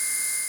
Elevator moving_short.ogg